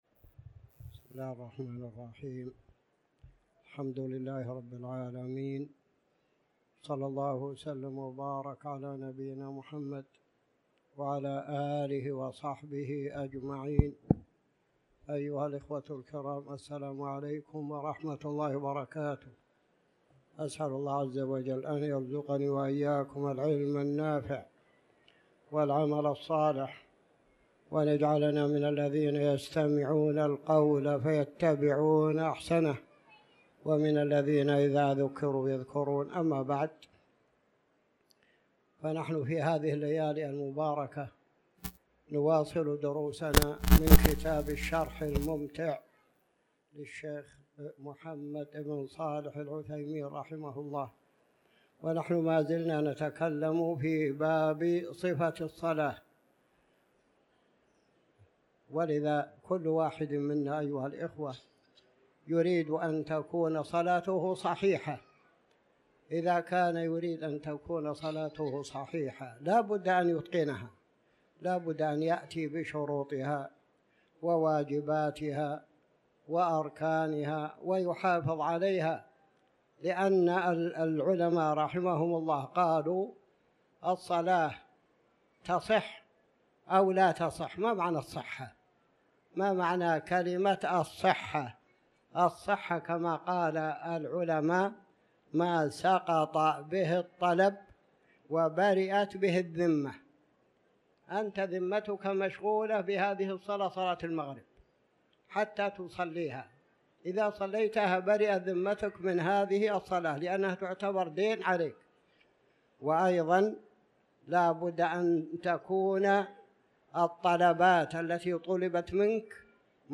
تاريخ النشر ٧ ذو القعدة ١٤٤٠ هـ المكان: المسجد الحرام الشيخ